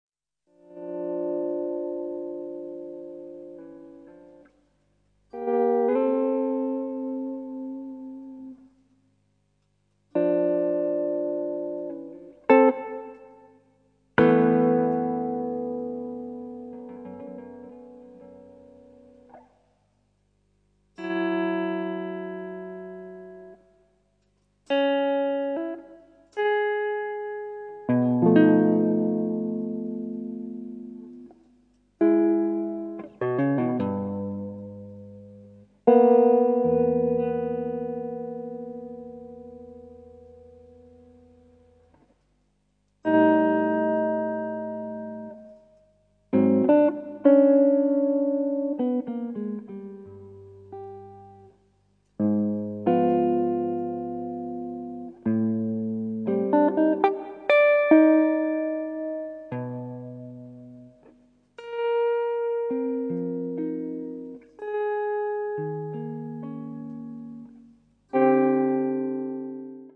guitar, electronics
tuba
drums, percussion